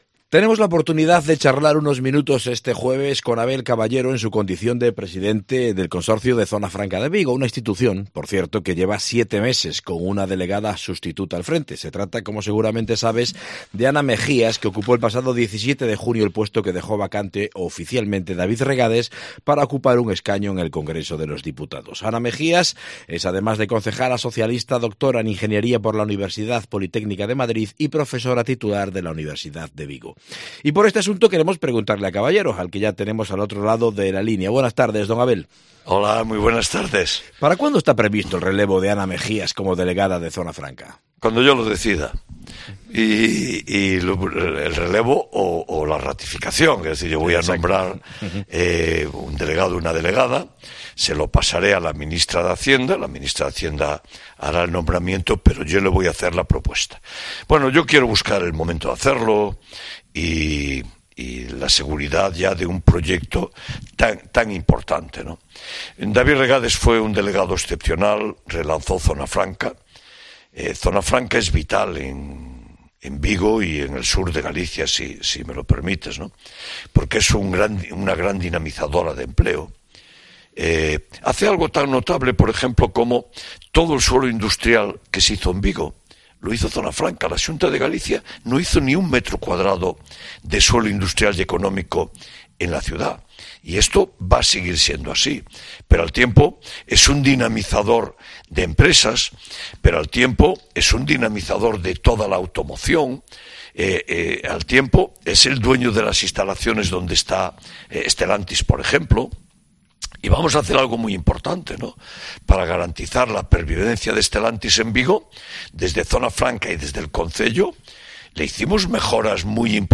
AUDIO: Entrevista con Abel Caballero, presidente de Zona Franca de Vigo